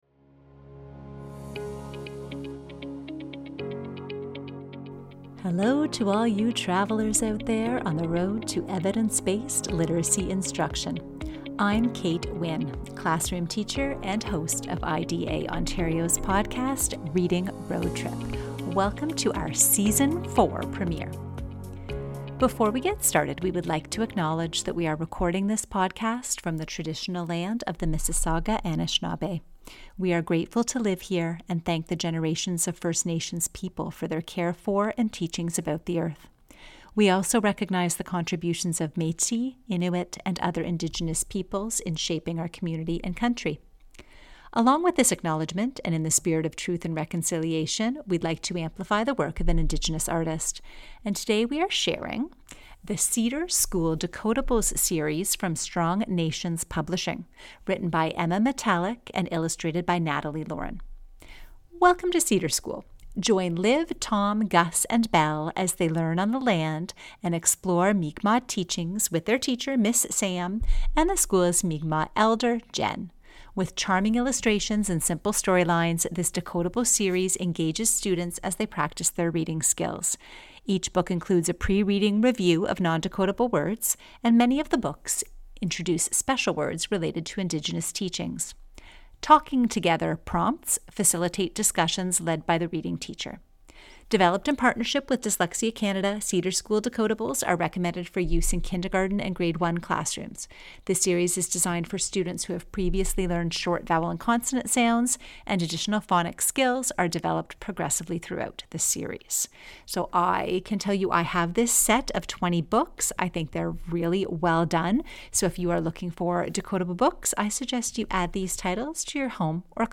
conversation about transforming writing instruction. From kindergarten to high school, discover how this evidence-based approach is scaling across classrooms, building confidence, reducing teacher workload, and aligning beautifully with Canadian curricula.